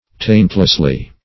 taintlessly - definition of taintlessly - synonyms, pronunciation, spelling from Free Dictionary Search Result for " taintlessly" : The Collaborative International Dictionary of English v.0.48: Taintlessly \Taint"less*ly\, adv.
taintlessly.mp3